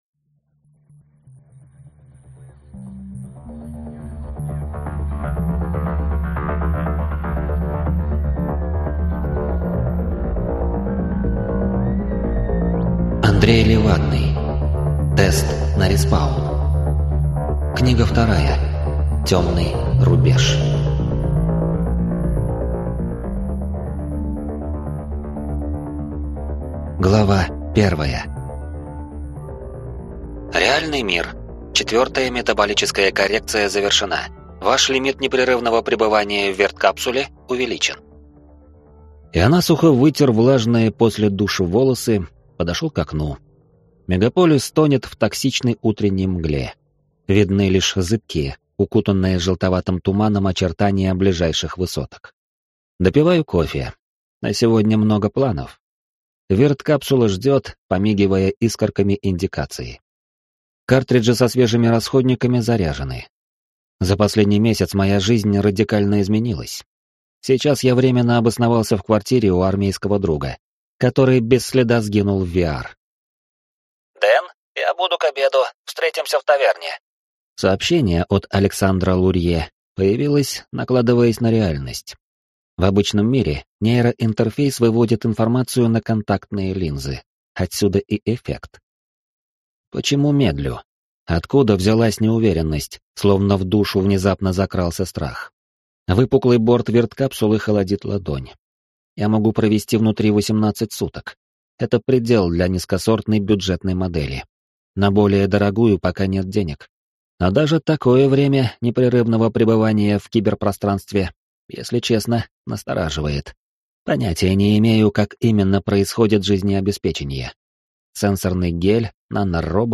Аудиокнига Тест на респаун. Темный рубеж | Библиотека аудиокниг